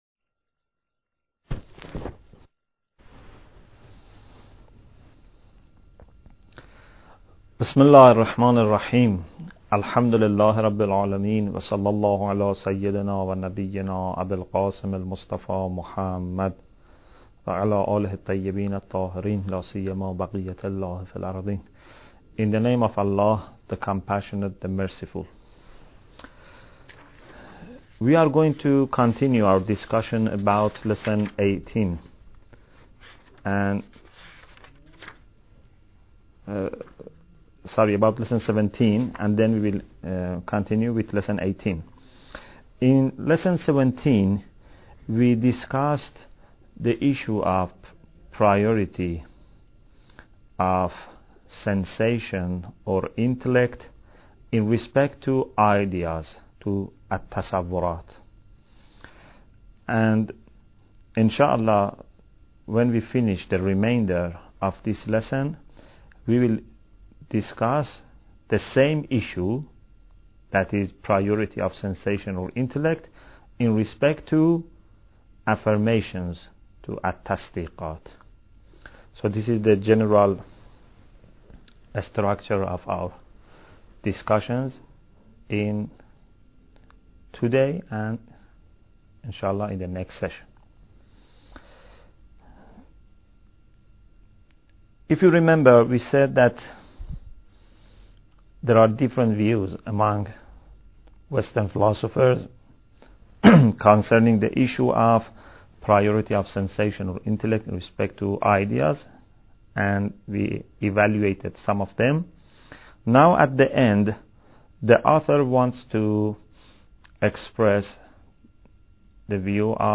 Bidayat Al Hikmah Lecture 21